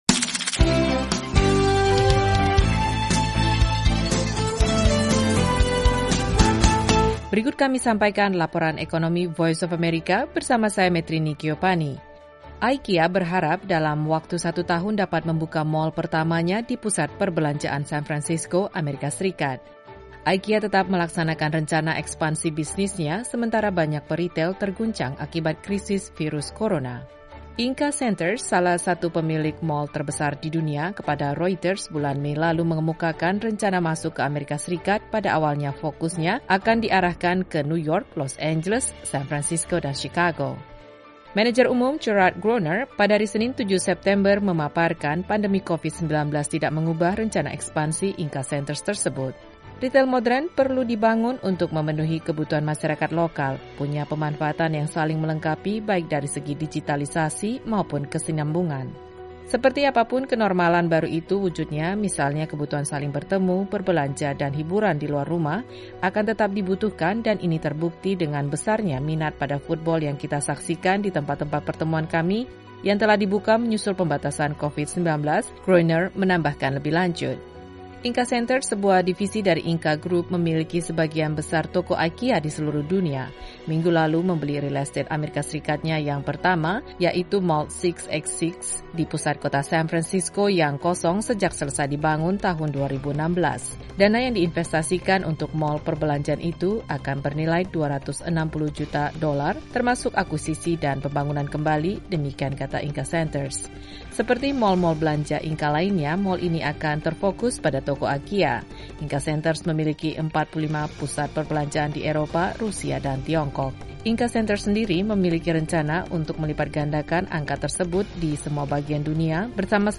Laporan Ekonomi VOA terkait pandemi yang tidak menghentikan rencana pusat perbelanjaan IKEA di Amerika Serikat.